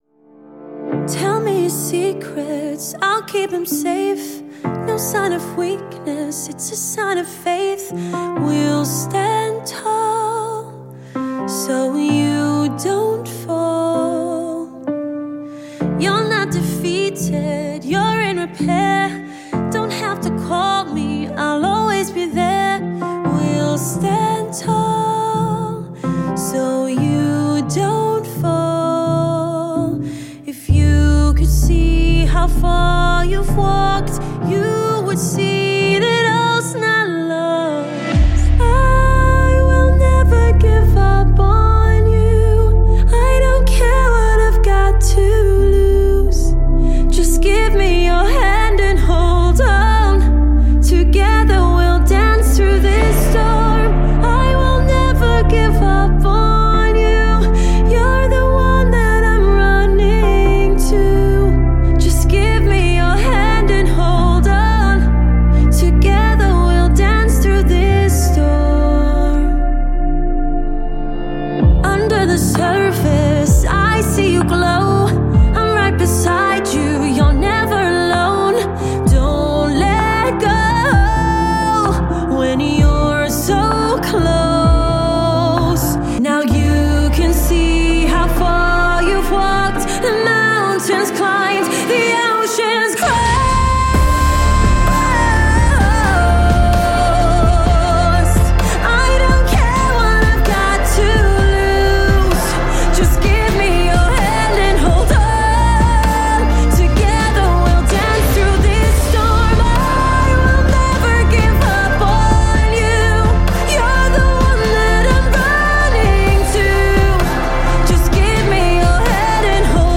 BPM64
Audio QualityMusic Cut
Some very slow, powerful chamber pop here.